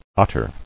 [ot·tar]